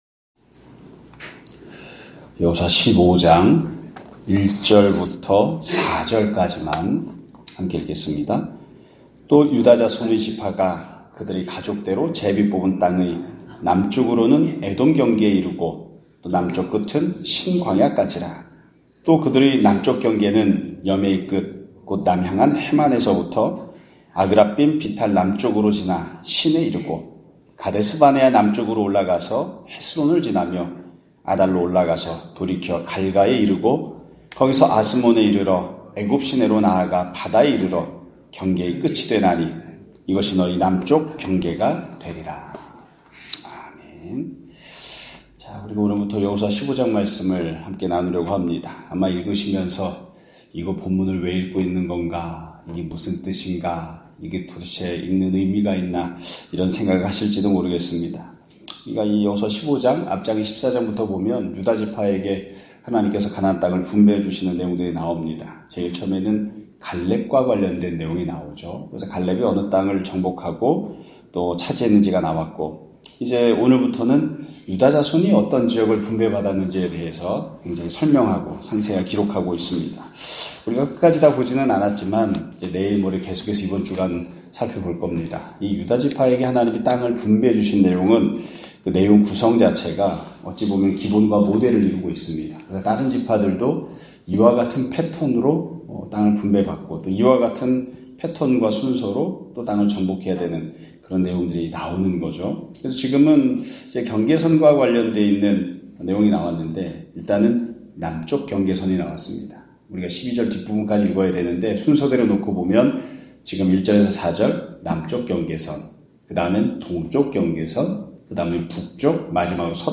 2024년 12월 2일(월요일) <아침예배> 설교입니다.